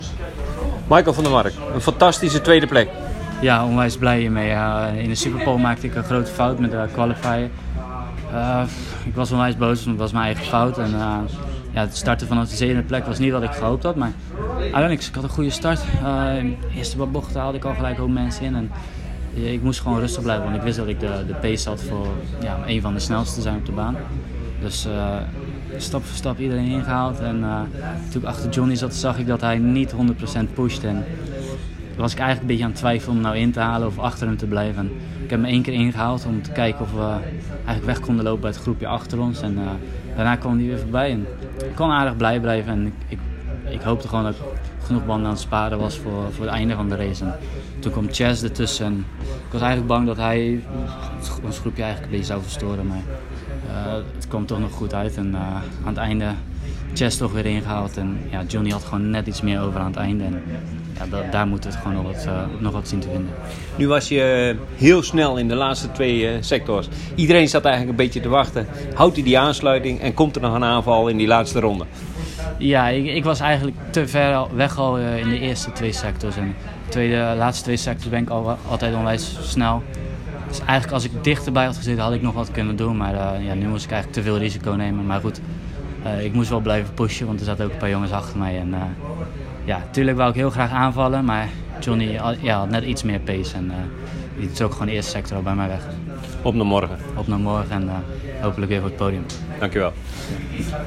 Direct na afloop van de wedstrijd spraken we kort met Van der Mark en vroegen hem naar een eerste reactie.